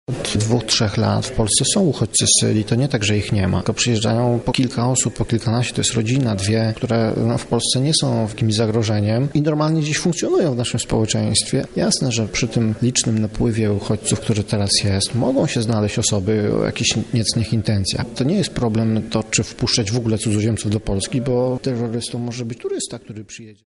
Ambasador Syrii odwiedził wczoraj Lublin w ramach debaty „Polityka imigracyjna: za czy przeciw”.